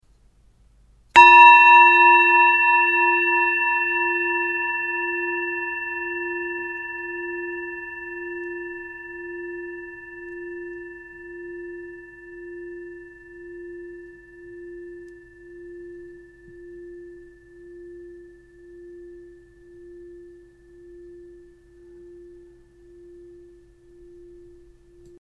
Tibetische Klangschale - HERZSCHALE
Gewicht: 638 g
Grundton: 349,87 Hz
1. Oberton: 958,29 Hz
M72-638-tibet-Klangschale.mp3